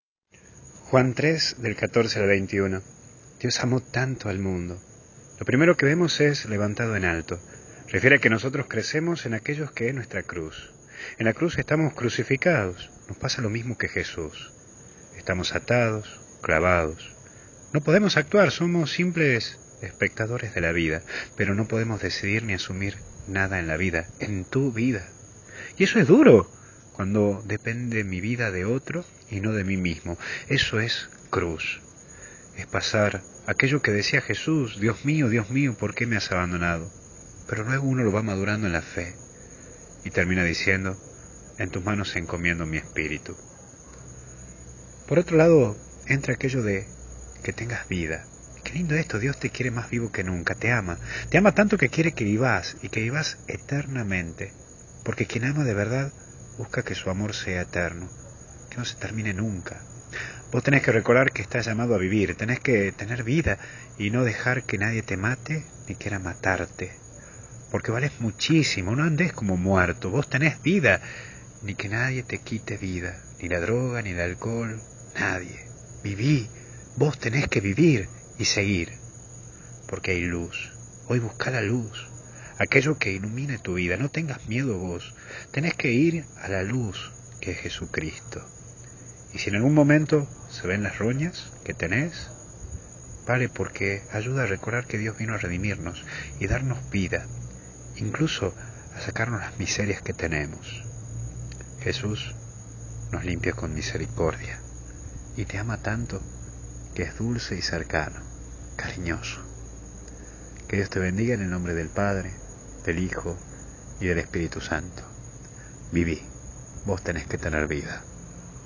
Meditación Diaria